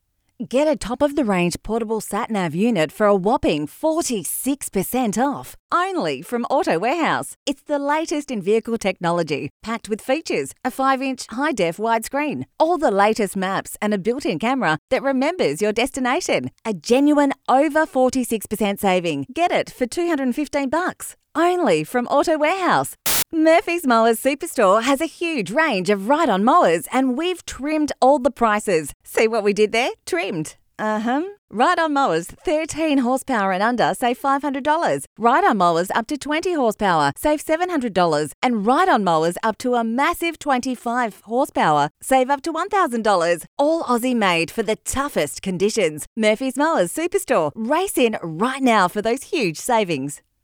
• Hard Sell
• Versatile